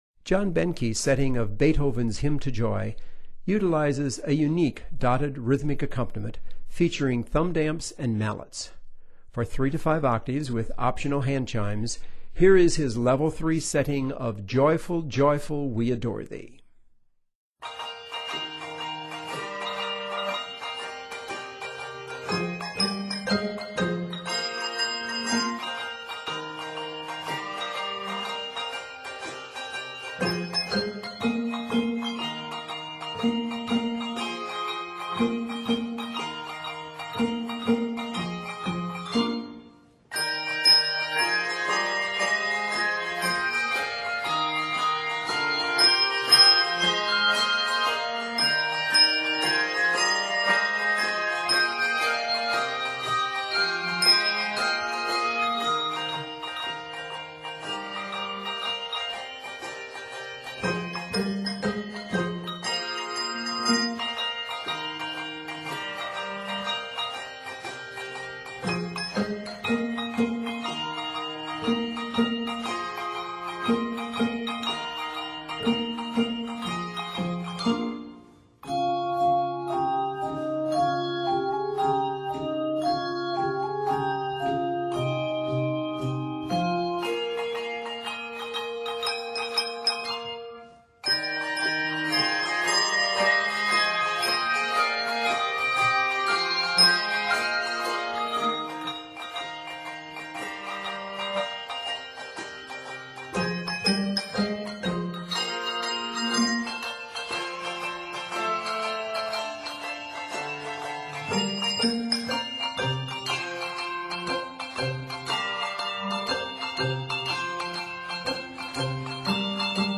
Scored in Eb Major, it is 96 measures.